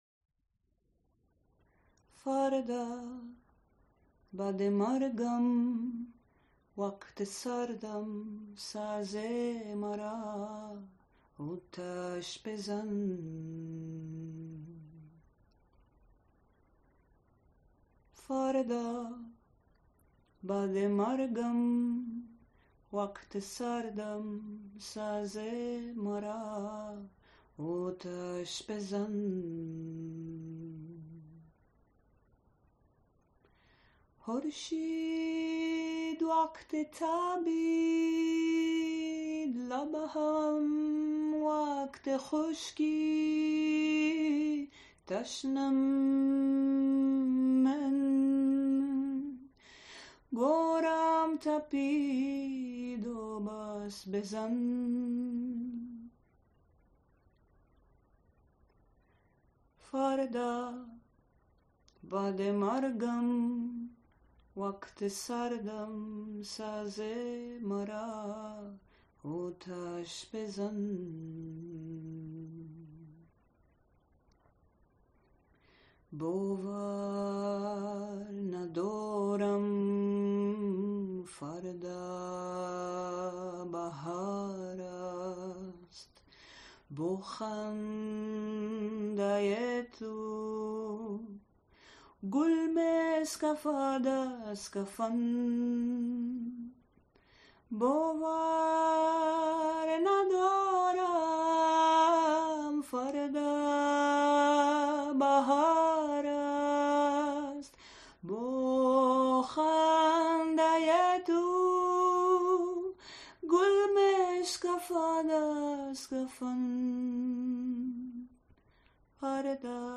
Composed by: Abi K. Safa, Mehran Fanoos Lyrics by: Noor Ahmad Payeez
Created by Afghani refugee musicians who fled their homeland due to persecution of artists